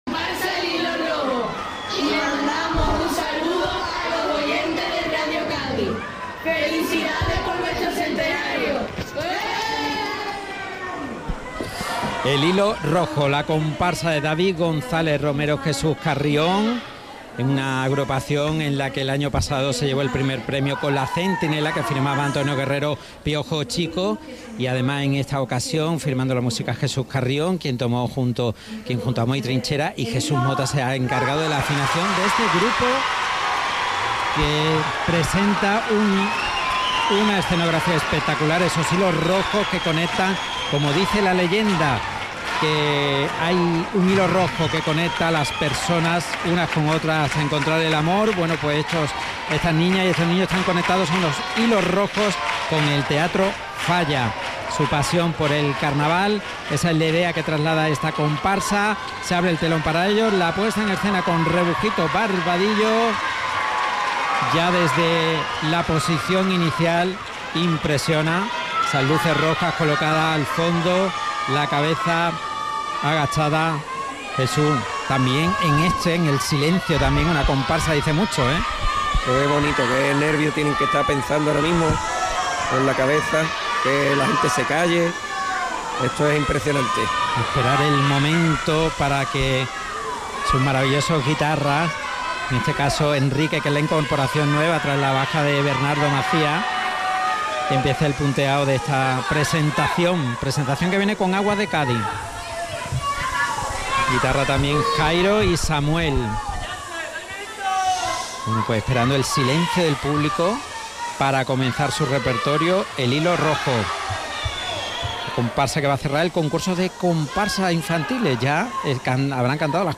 Comparsa Infantil - El hilo rojo Final